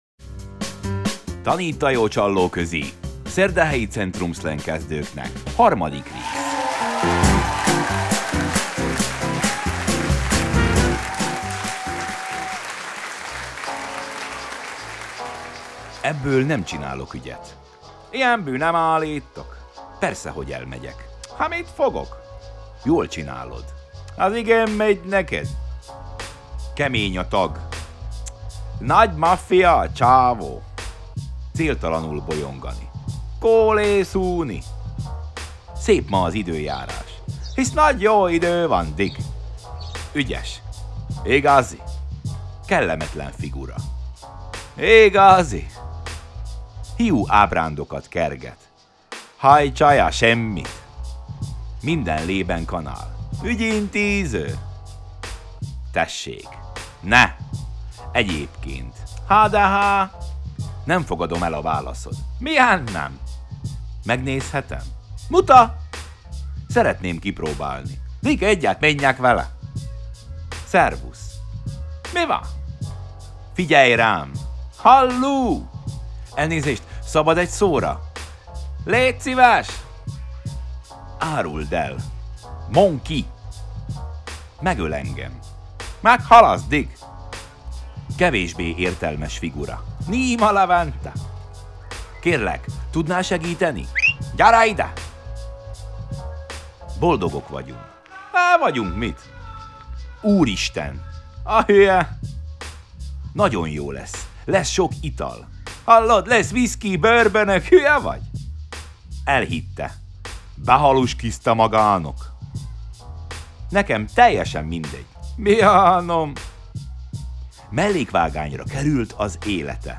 The Pharcyde - She Said (Instrumental)
Fun Retro Motown - Upbeat